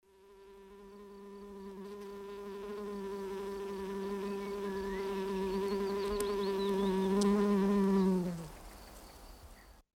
دانلود صدای مرغ عشق و قناری برای آرامش و تمدد اعصاب از ساعد نیوز با لینک مستقیم و کیفیت بالا
جلوه های صوتی